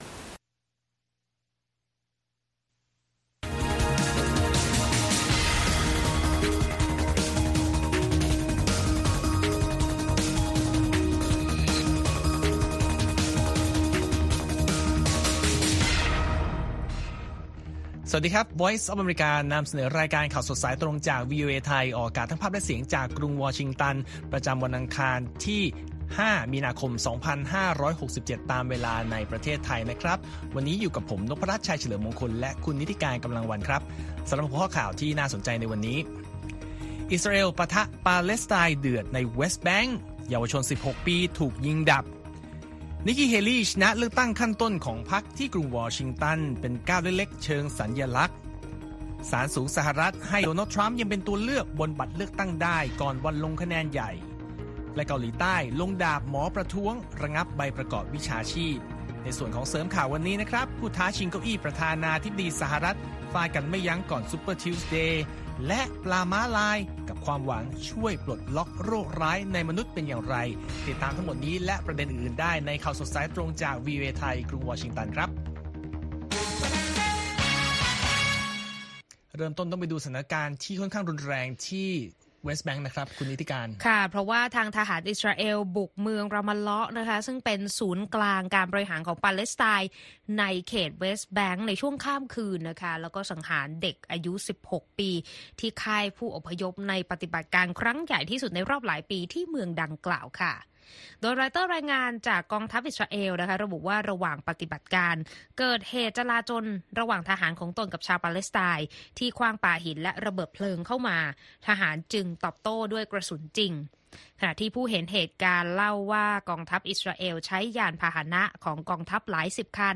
ข่าวสดสายตรงจากวีโอเอไทย 8:30–9:00 น. วันอังคารที่ 5 มีนาคม 2567